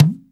TABLA 14.WAV